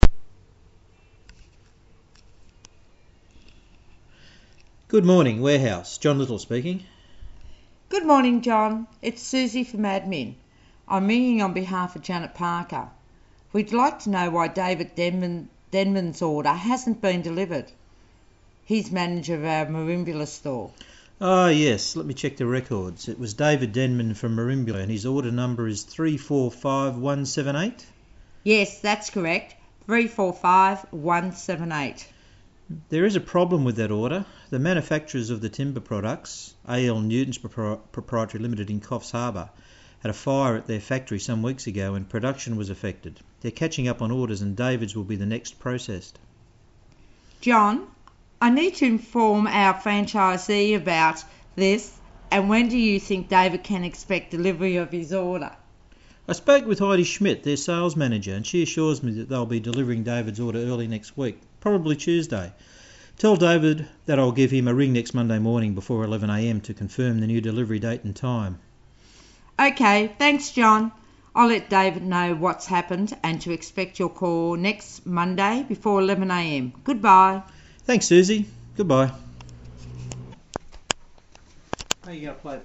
phone conversation